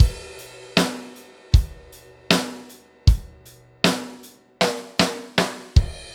Track 07 - Drum Break 02.wav